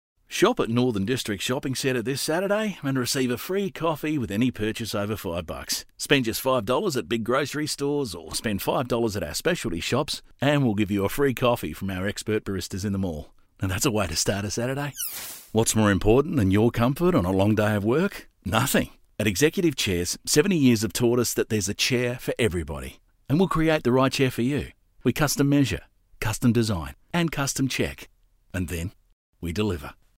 I guess you can call me ‘the everyday bloke’.
• Conversational